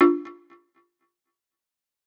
Perc Zion.wav